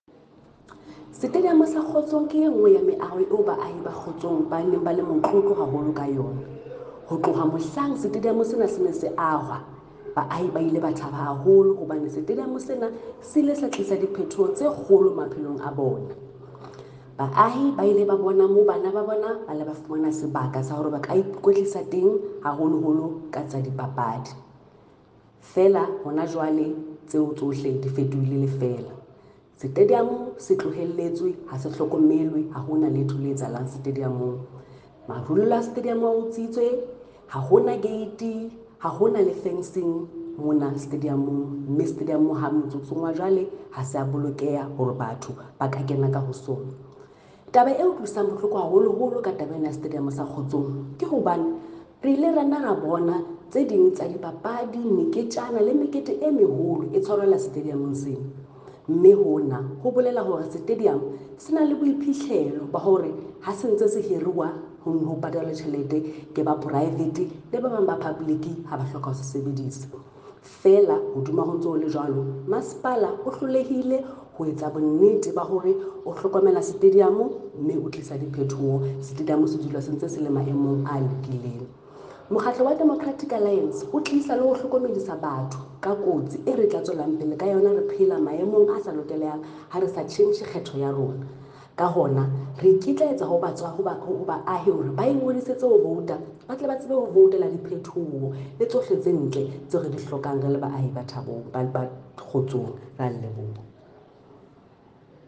Sesotho soundbites by Cllr Mahalia Kose.